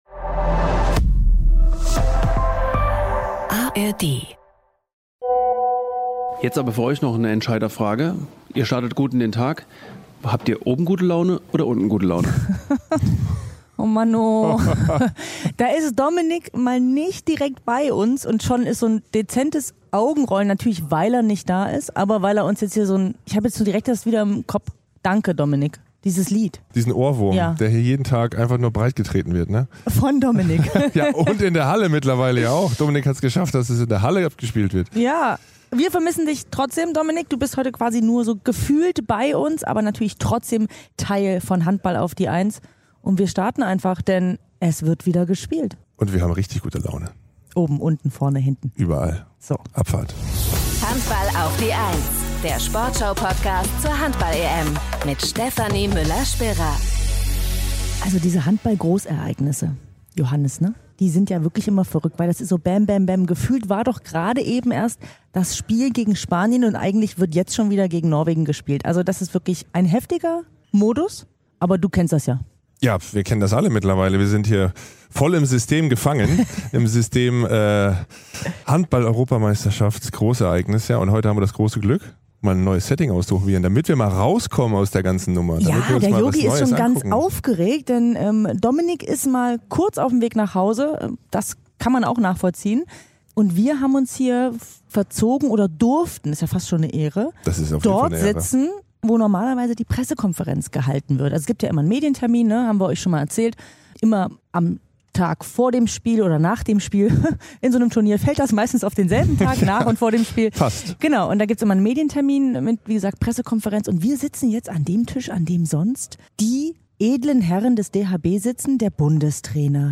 Bei den Füchsen Berlin ist Matthes Langhoff schon länger eine feste Größe – nun auch in der DHB-Auswahl. Im Interview verrät er, wie er von seiner Nominierung erfahren hat.